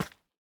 Minecraft Version Minecraft Version 1.21.5 Latest Release | Latest Snapshot 1.21.5 / assets / minecraft / sounds / block / candle / step3.ogg Compare With Compare With Latest Release | Latest Snapshot
step3.ogg